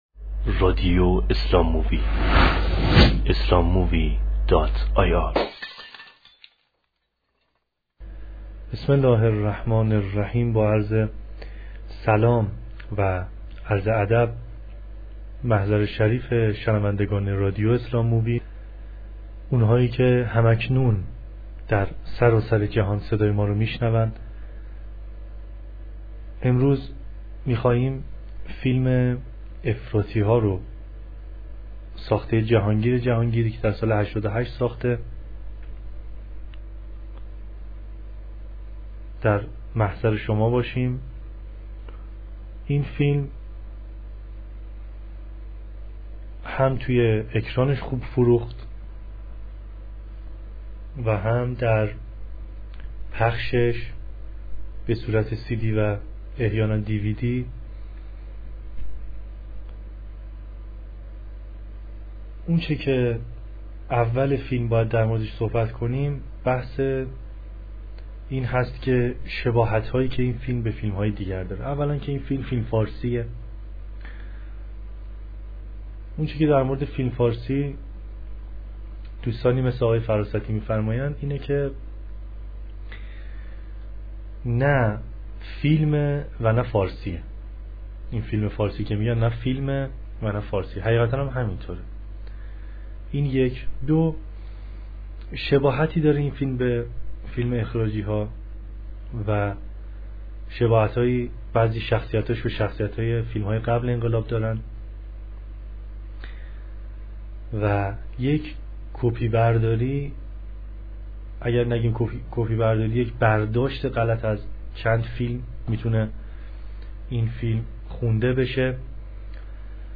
نقد صوتی فیلم ایرانی “افراطی ها” – مجله نودیها